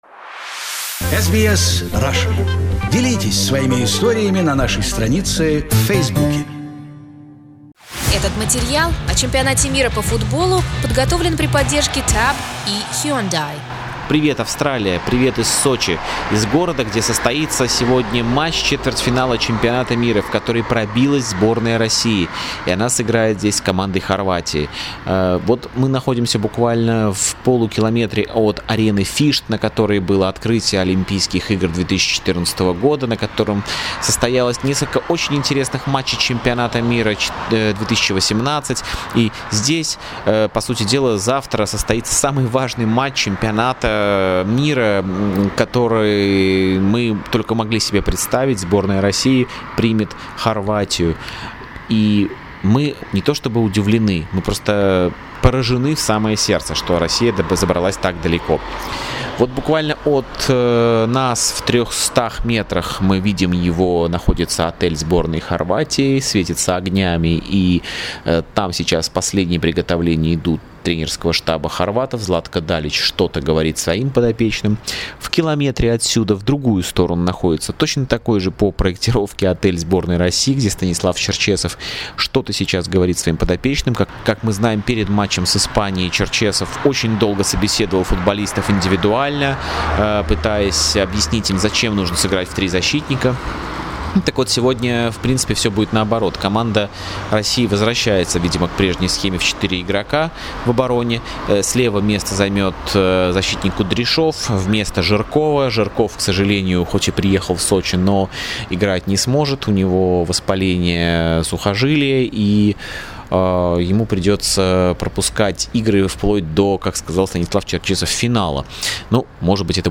De Bruyne stunner sees Belgium shock favourites Brazil 2-1 and France cruise into semi-finals 2-0 against Uruguay with help from Muslera howler. And now all eyes are on the host nation clashing very soon with Croatia in Sochi. Talking Football - our correspondent at WC Russia 2018